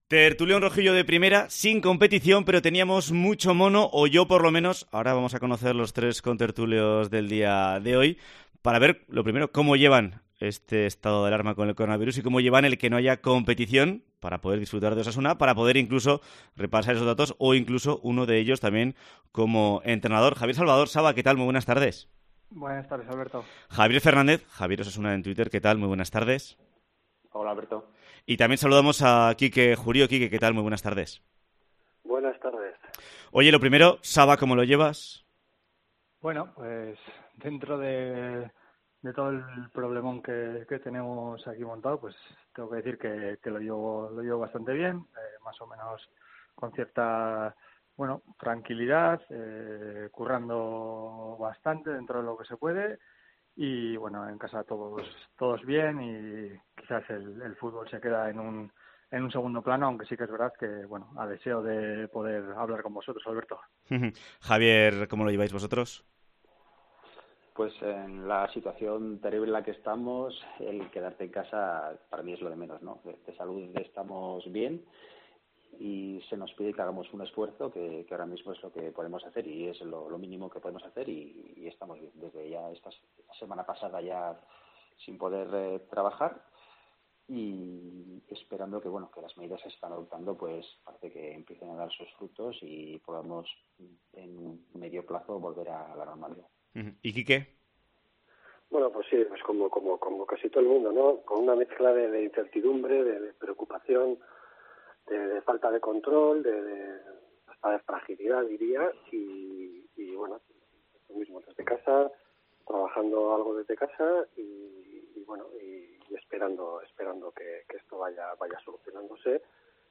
Bajo el patrocinio del PTV, Pamplona, Tapas y Vinos pasan por el estudio exjugadores del conjunto rojillo, socios y aficionados para opinar sobre el encuentro disputado por el equipo navarro.